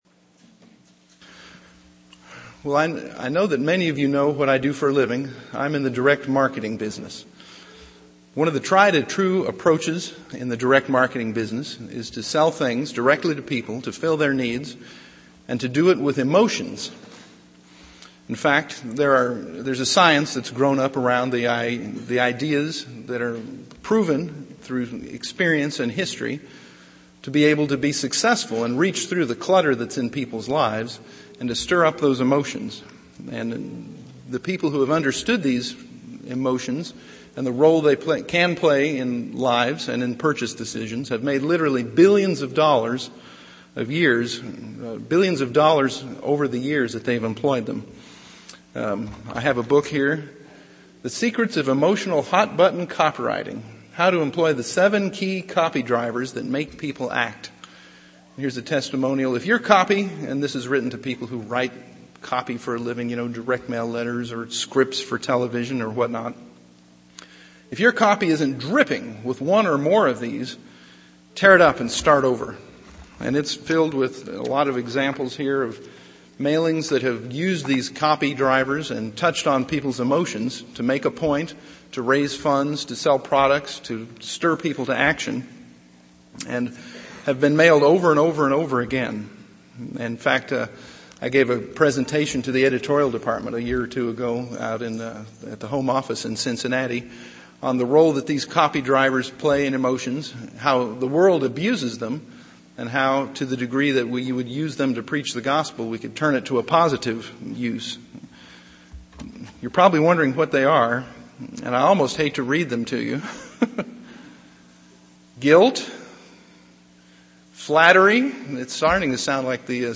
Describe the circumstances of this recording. Given in Ft. Wayne, IN